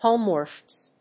音節hóme・wards 発音記号・読み方
/‐wɚdz(米国英語), ‐wədz(英国英語)/
音節home･wards発音記号・読み方hóʊmwərʣ